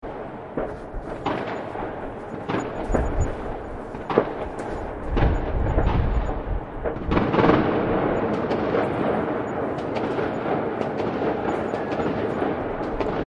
烟花在城市2
描述：新的一年烟花在城市的声音